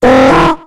Cri de Bekipan dans Pokémon X et Y.